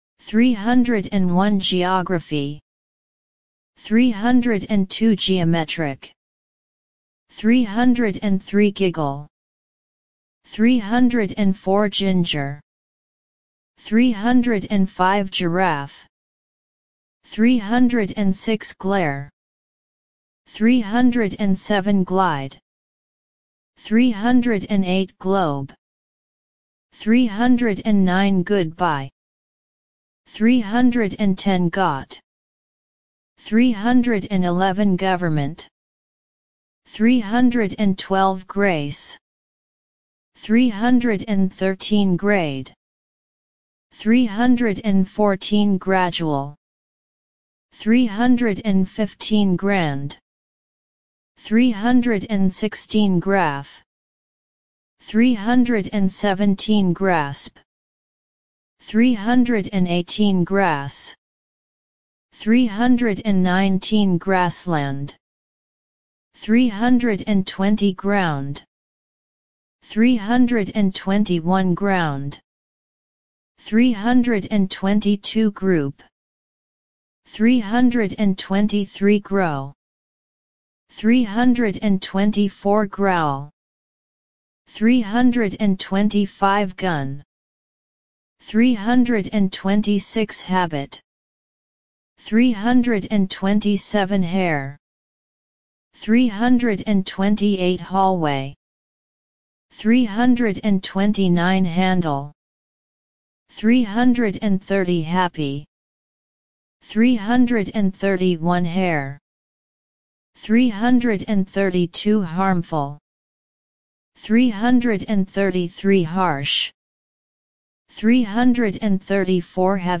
301 – 350 Listen and Repeat